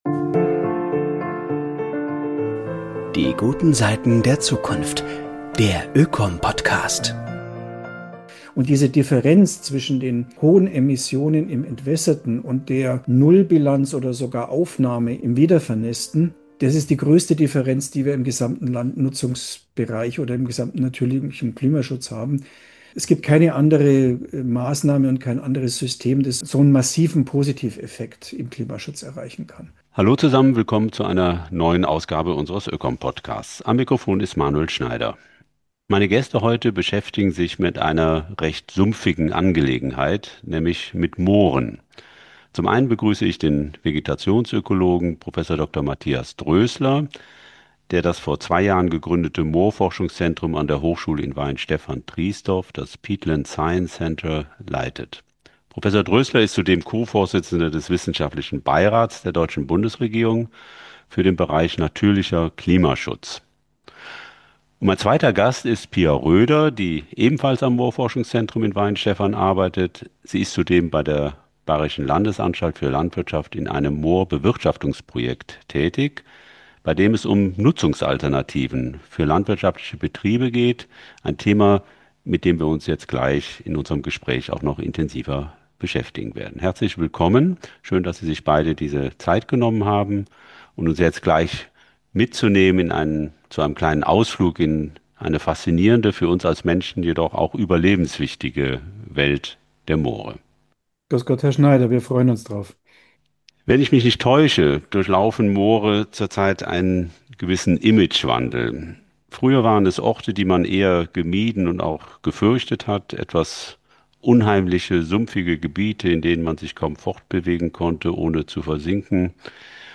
Mehr Moor wagen! Über den Beitrag von Mooren zum Klimaschutz [Gespräch